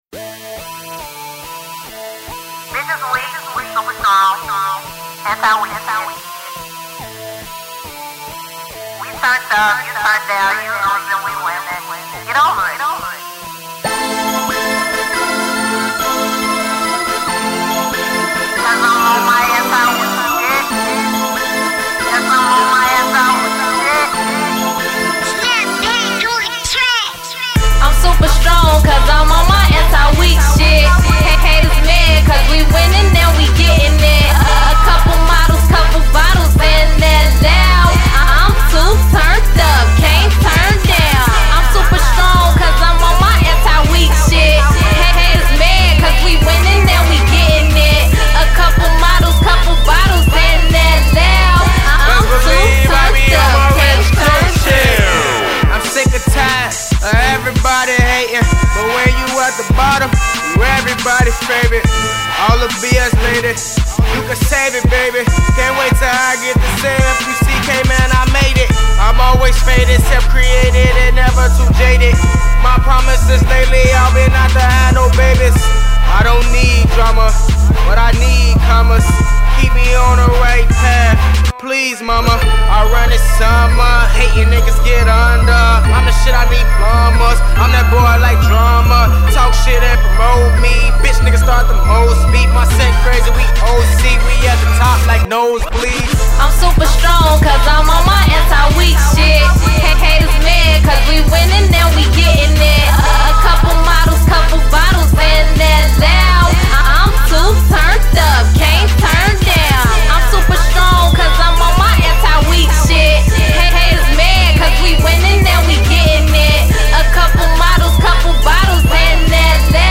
turnt up instrumental